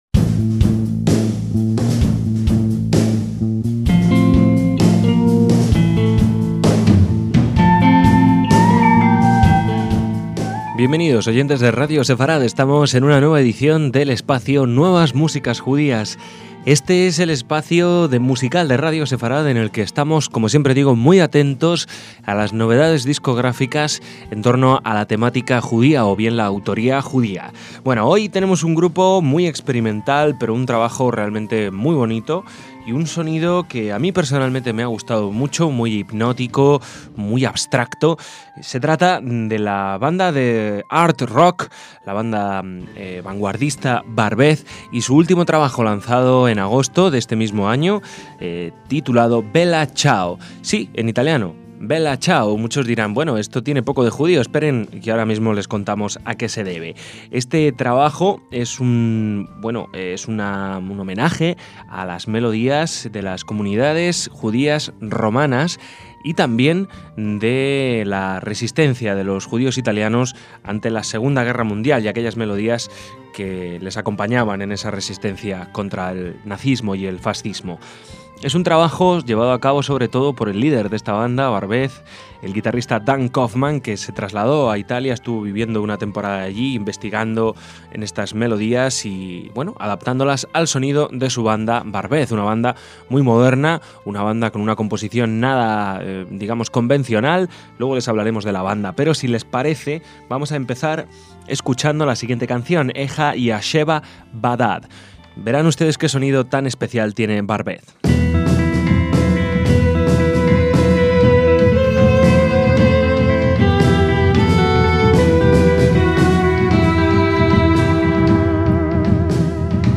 antiguas melodías de los judíos romanos
contrabajo
marimba, vibráfono, órgano y piano
batería y tímpanos
clarinetes
violín
theremin